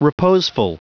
Prononciation du mot reposeful en anglais (fichier audio)
reposeful.wav